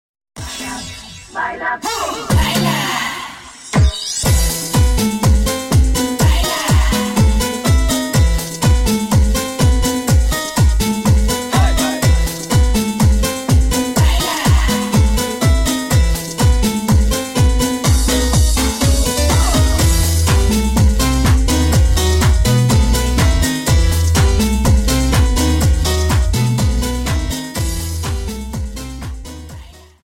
Cha Cha Song